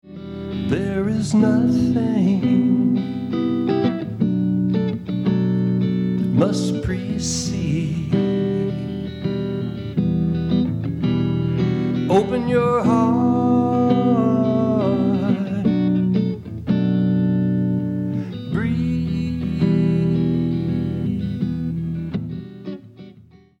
Original Song from Fundraiser Concert 2016